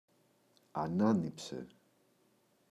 ανάνηψε [a’nanipse]